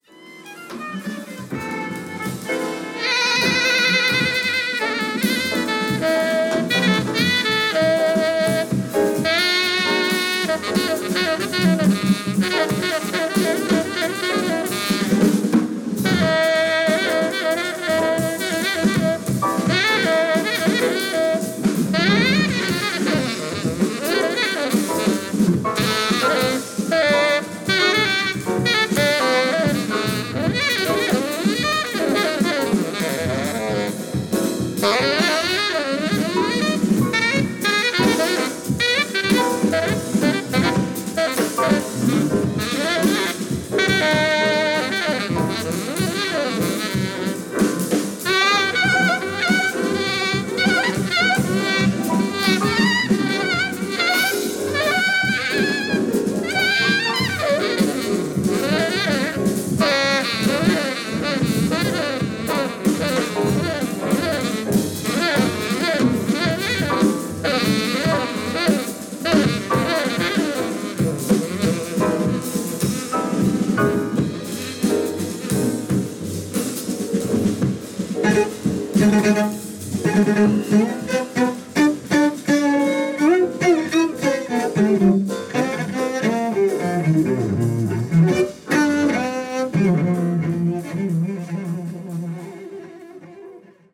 Cello
Bass
Drums
Saxophone
Violin
Piano